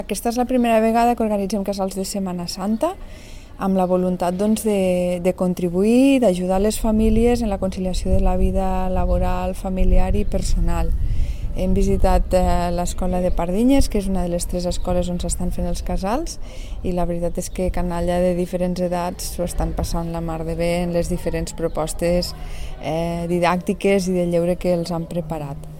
tall-de-veu-de-la-tinent-dalcalde-sandra-castro-sobre-els-casals-de-setmana-santa-organitzats-per-la-paeria